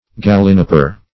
Gallinipper \Gal"li*nip`per\, n. A large mosquito.